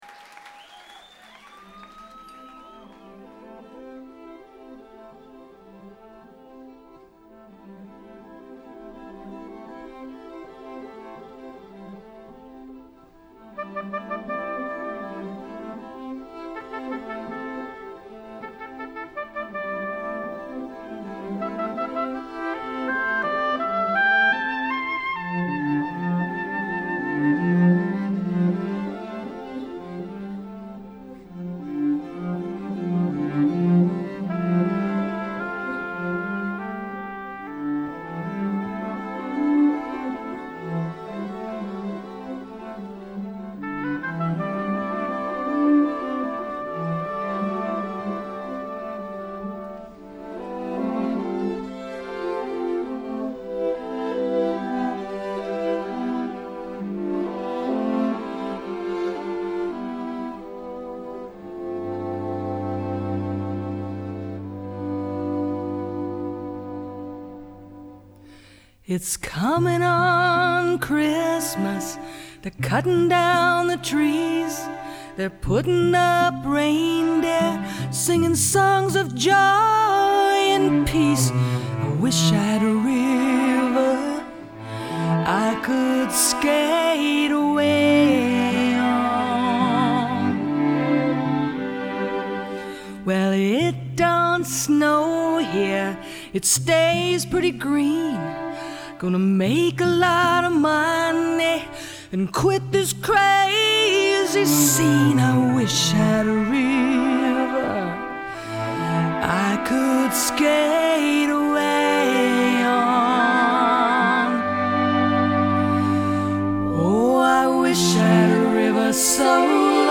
sang the song live in the 1990s
typically peerless vocal
live album